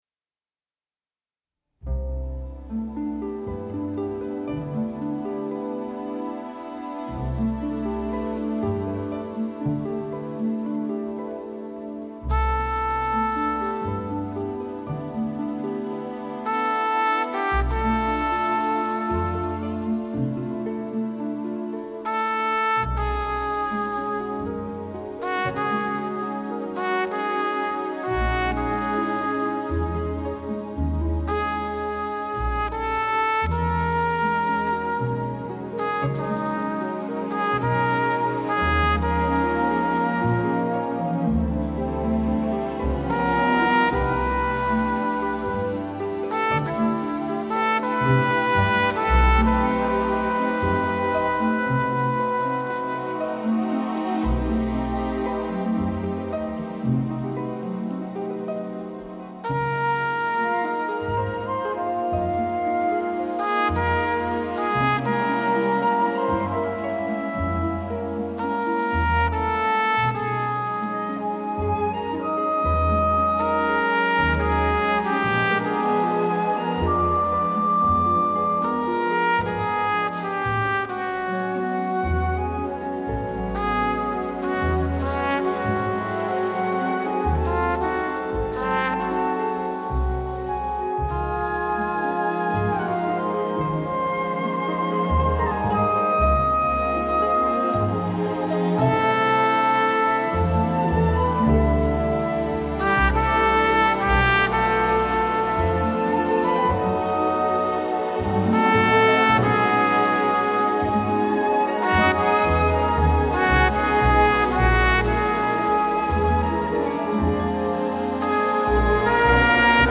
Klasika